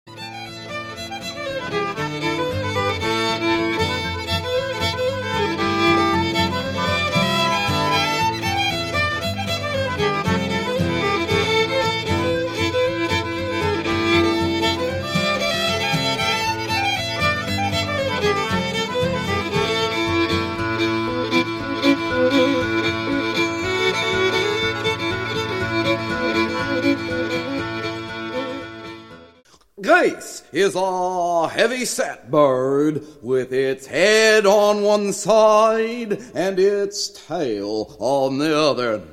34 Years of Tuesday Night Storytelling